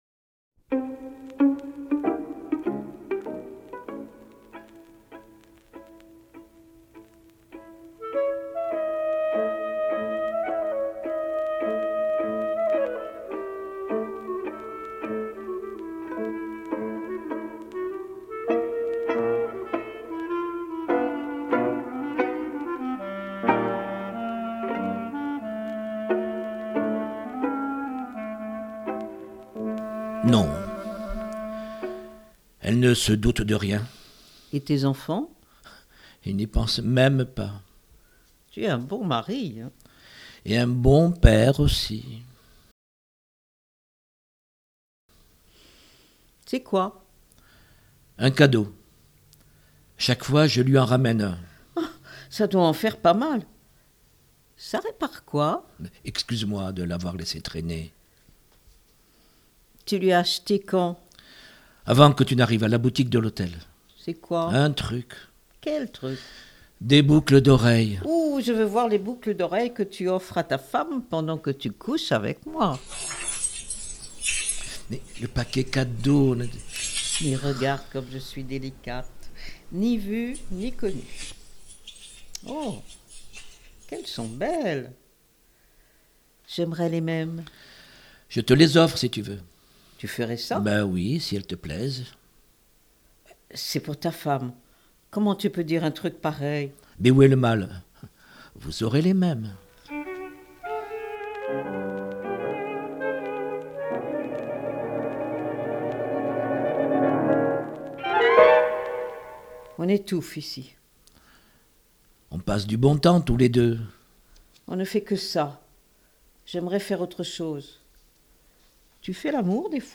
Groupe-lecture-Entendre-des-voix.mp3